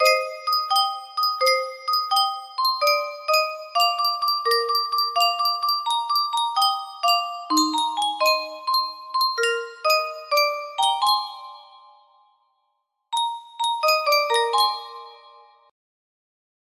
Yunsheng Music Box - Unknown Tune 1727 music box melody
Full range 60